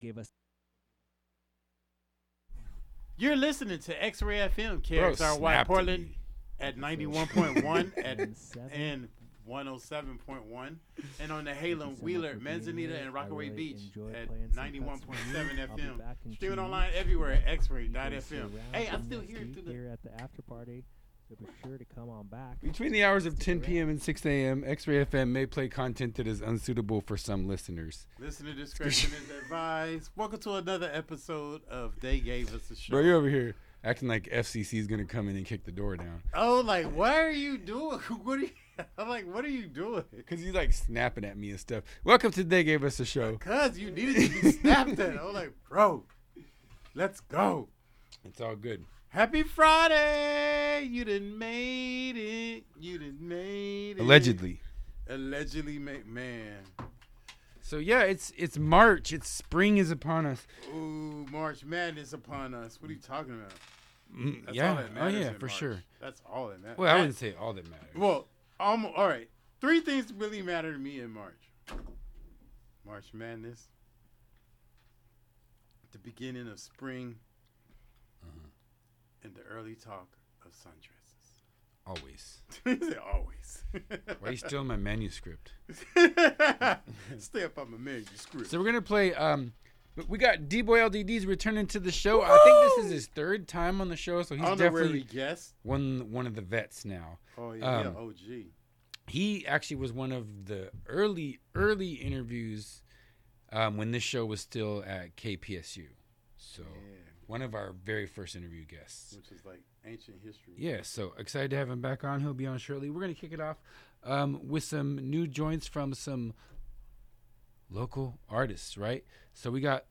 New and throwbacks, from Portland and beyond. Plus, catch interviews from talented artists.